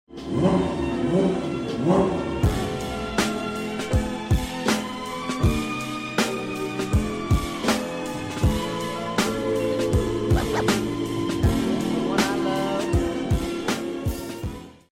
Porsche 992.1 GT3 Touring modified